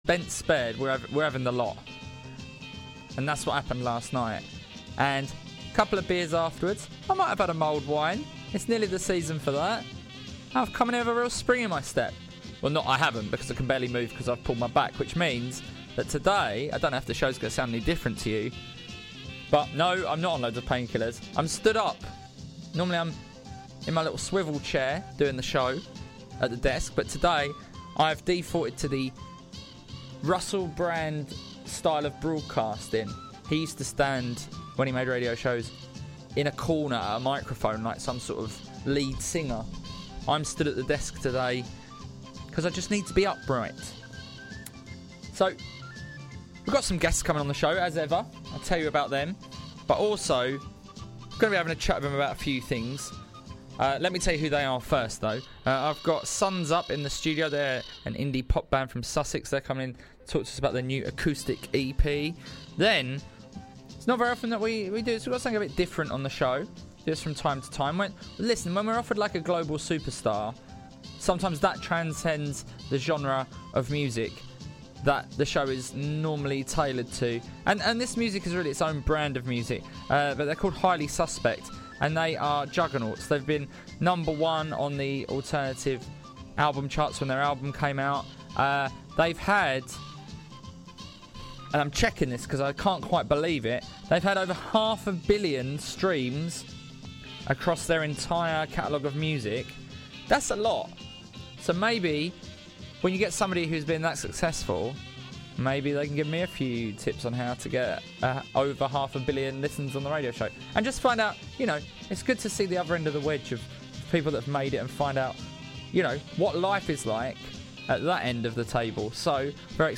The Mimic Terry Mynott mocks beloved and less beloved stars whilst bantering with his co-hosts